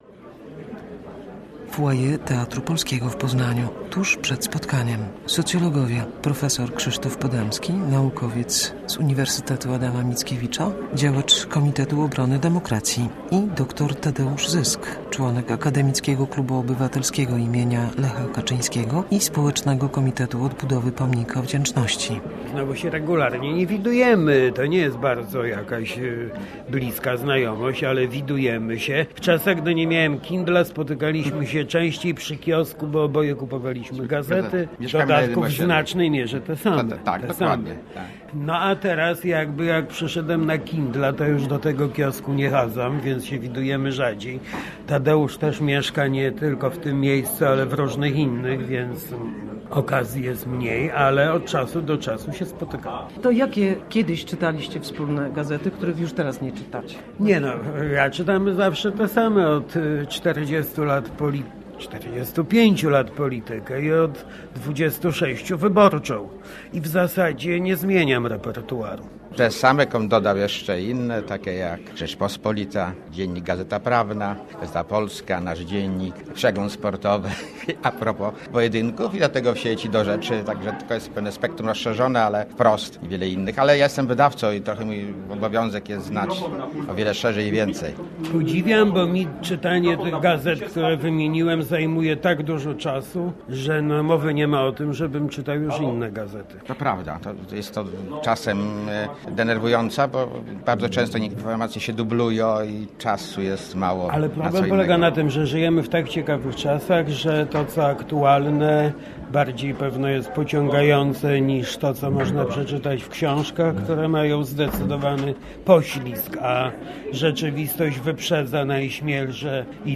AKO-KOD - spotkanie - reportaż - Radio Poznań